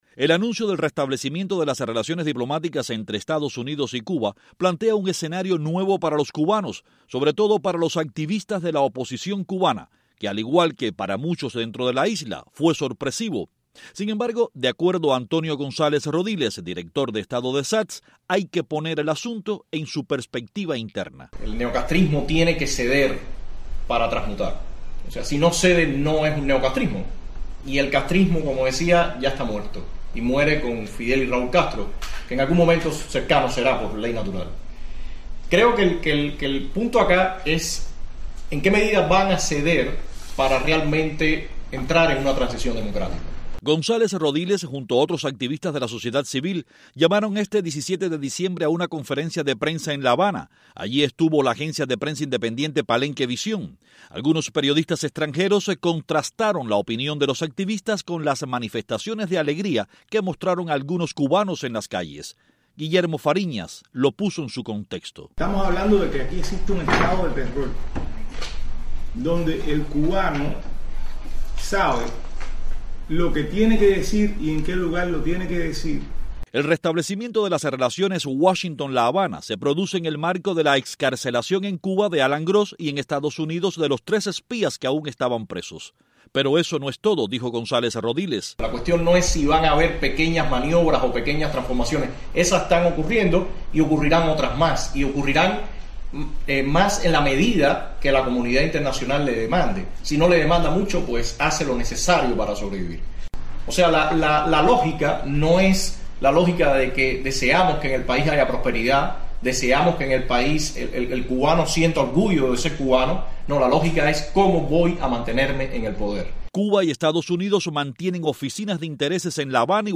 Conocidos activistas de la sociedad civil en Cuba llaman a conferencia de prensa en La Habana y exponen sus puntos de vista sobre las nuevas relaciones “Cuba-Estados Unidos”.